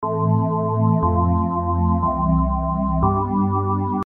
The following audio chord progression examples are based on the assumption that the root note is C.
For emotional ballads, the vi-IV-I-V progression is often utilized, creating a poignant and moving atmosphere.
vi-IV-I-V.mp3